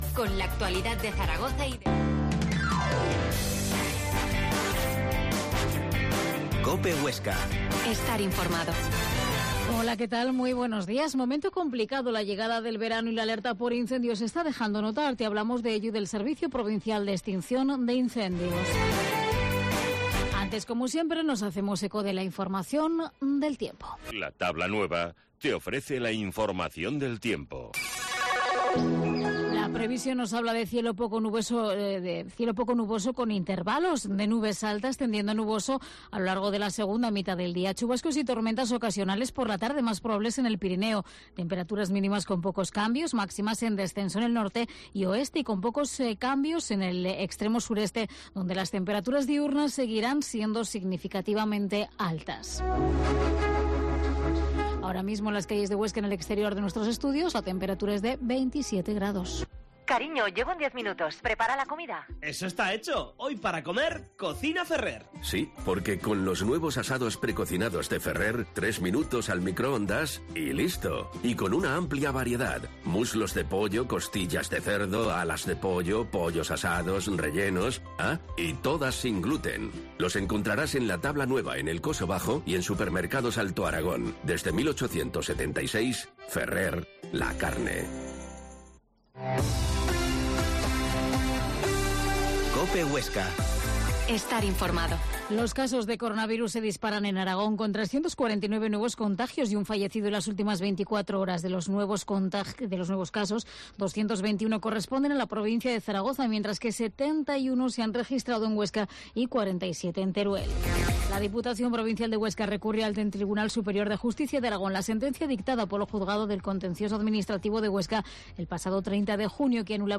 Herrera en Cope Huesca 12,50h. Entrevista al diputado del servicio de extinción de incendios